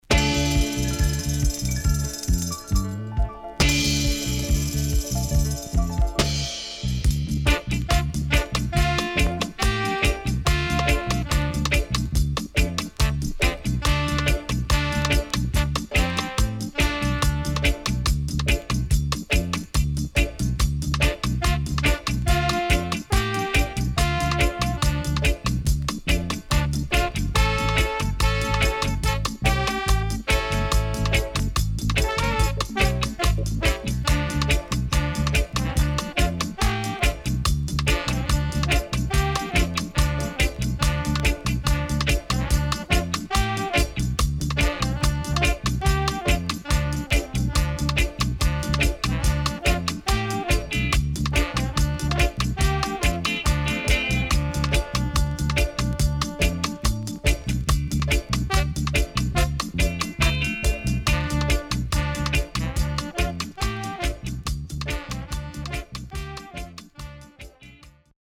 SIDE B:少しノイズ入ります。